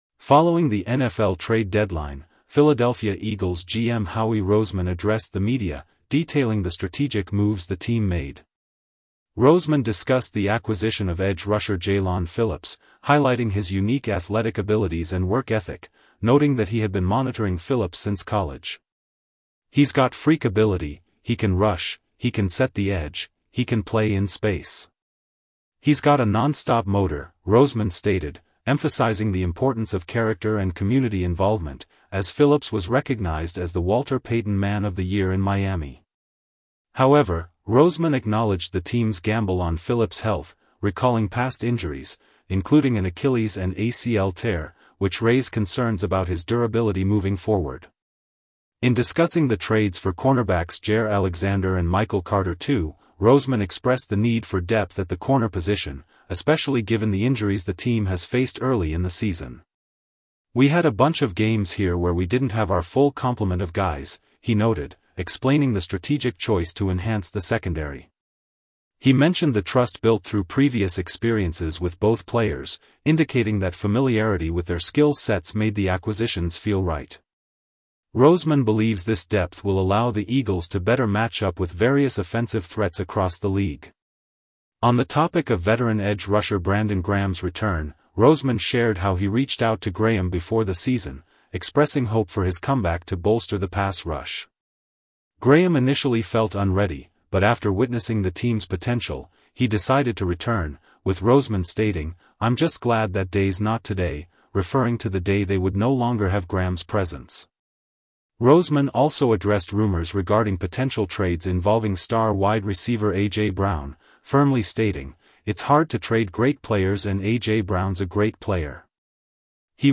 Following the NFL trade deadline, Philadelphia Eagles GM Howie Roseman addressed the media, detailing the strategic moves the team made.